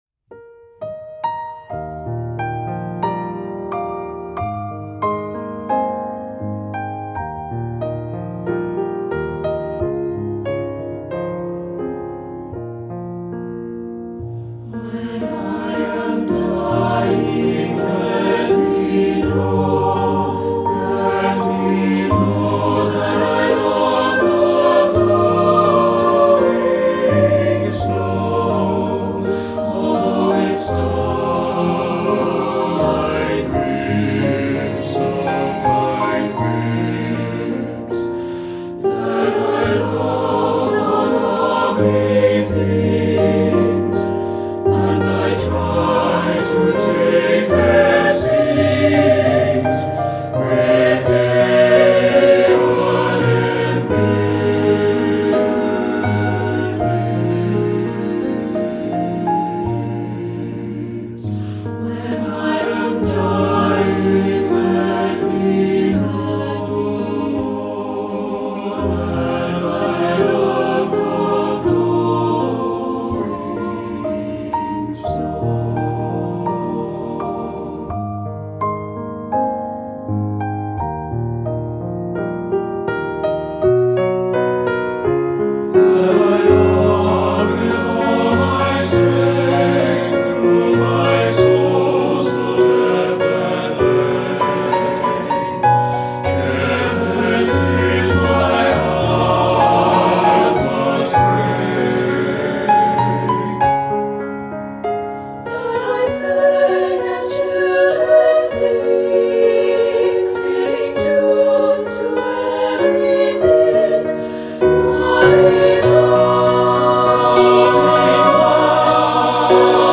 Choral Concert/General
SATB